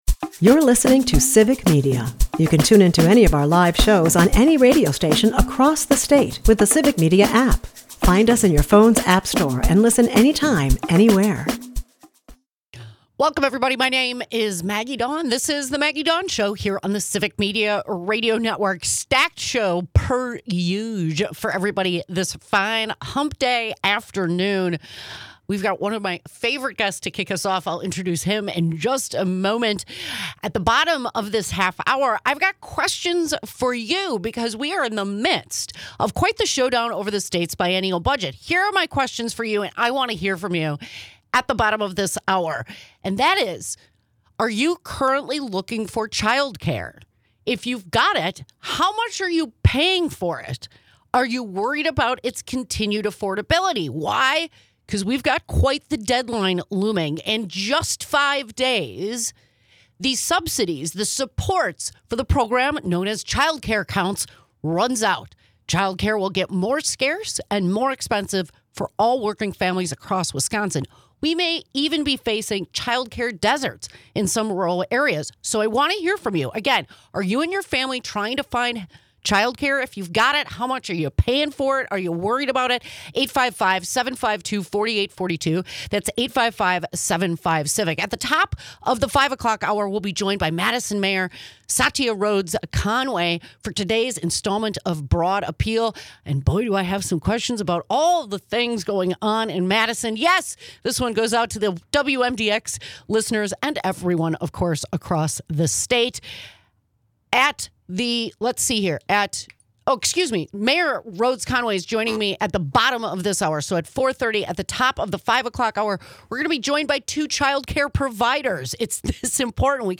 Madison Mayor Satya Rhodes-Conway joins the conversation with "Broad Appeal" to highlight how state and federal budget decisions affect local communities, from housing to shared revenue.